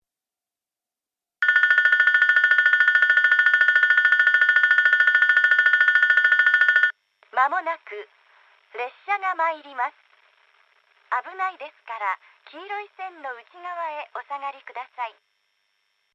接近放送　女声 放送は九州東邦型を使用していましたが、2012〜2013年頃に九州カンノ型Aに置き換えられていました。
なお、男声は無く実際の放送は4回流れます。スピーカーもユニペックスラッパ型のまま変更ありませんでした。